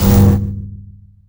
cyber_explode.wav